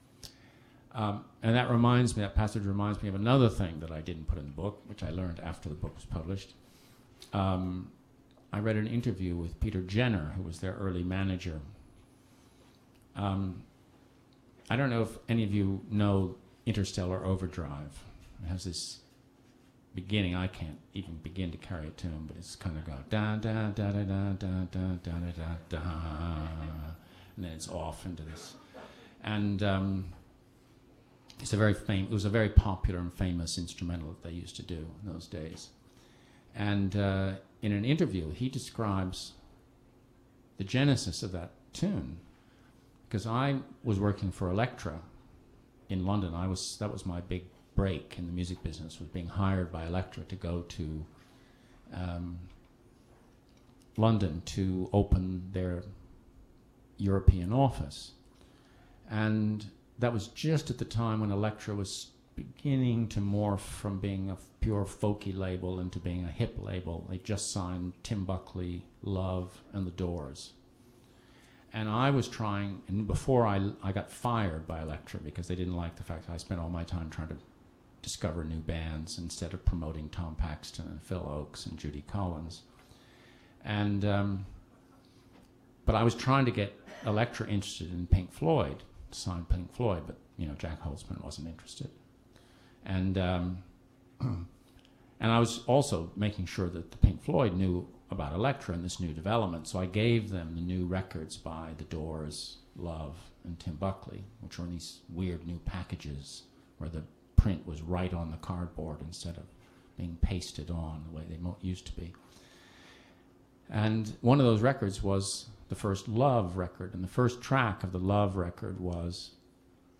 The premise of the book, which we here see Joe reading from at the Soho McNally Robinson bookstore in NYC, is that the 60's started in 1956, when the arrival of Dick Clark's sanitized version of rock'n'roll created an underground consciousness which clung to its gritty roots of blues and folk for sustenance; peaked on July 1 1967, with a cathartic performance by the group Tomorrow at UFO; and petered out in 1973, as oil embargoes, inflation, etc starved out the last of it's hedonistic anarchistic lifeblood.